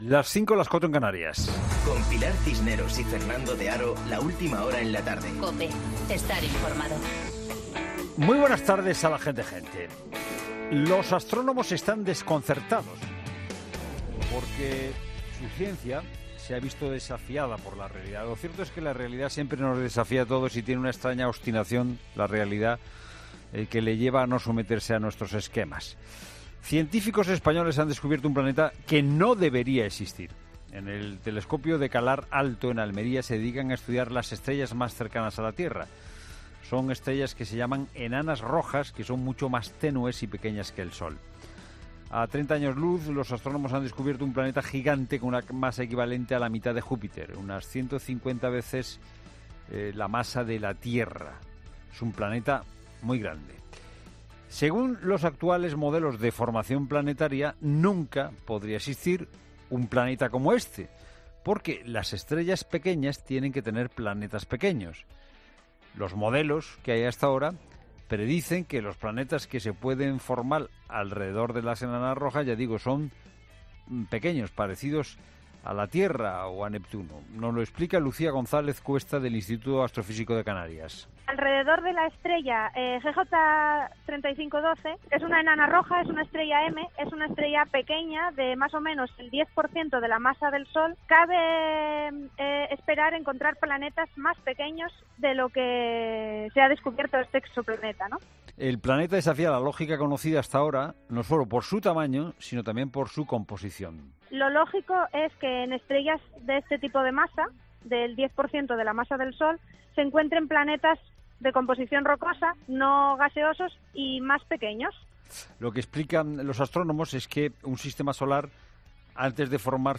Boletín de noticias COPE del 27 de septiembre a las 17.00